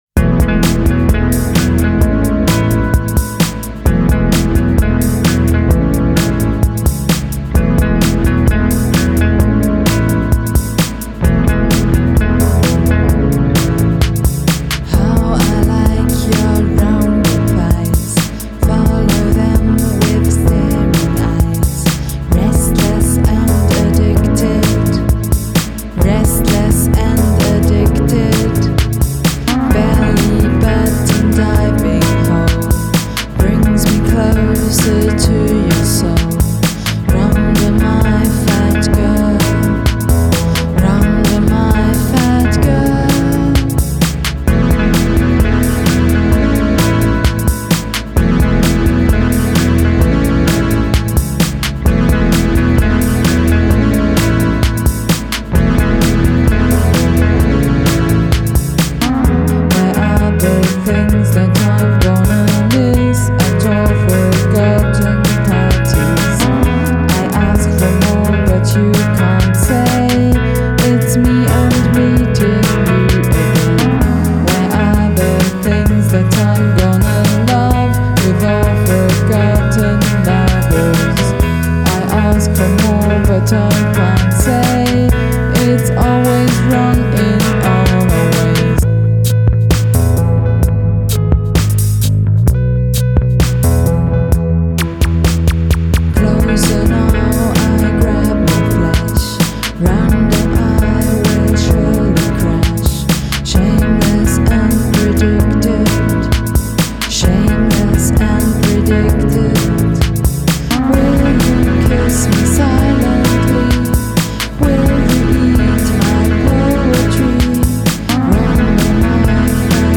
Ansi rafmagna� st�ff.
Afar hressandi rafmagn.